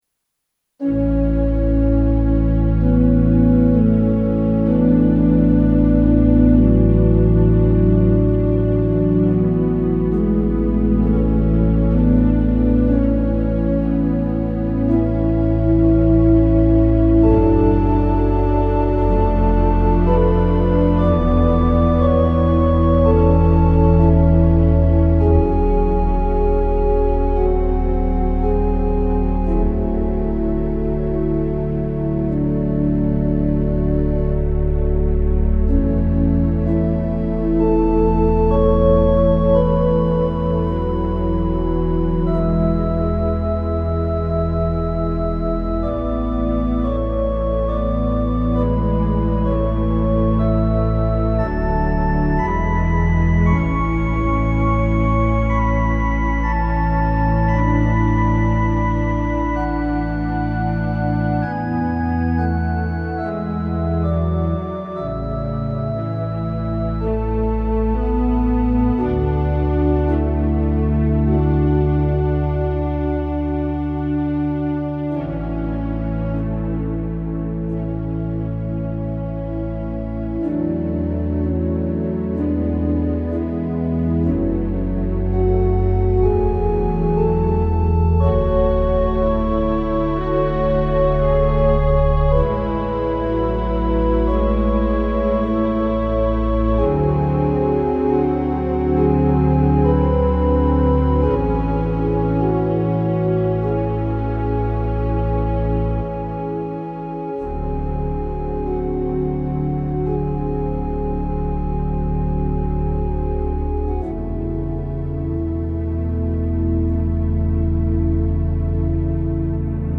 Organ Interludes Audio Gallery